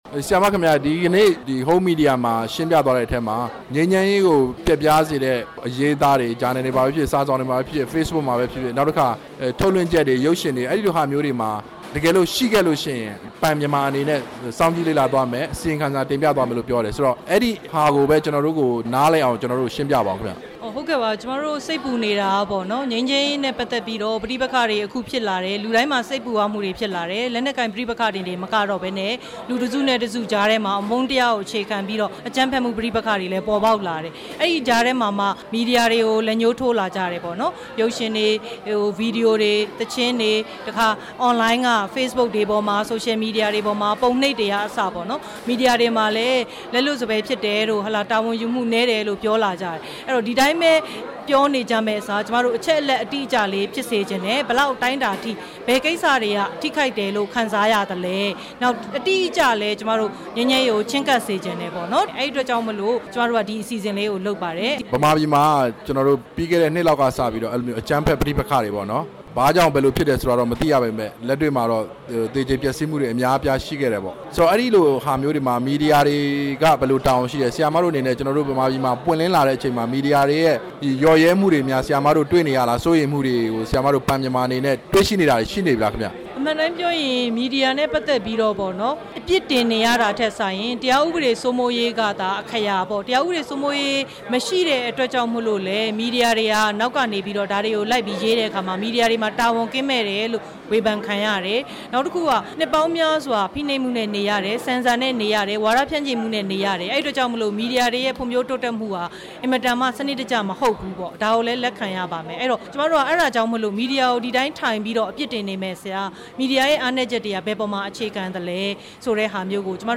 စာရေးဆရာမ မသီတာ(စမ်းချောင်း)နဲ့ မေးမြန်းချက်